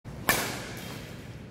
알림음(효과음) + 벨소리
알림음 8_셔틀콕1.mp3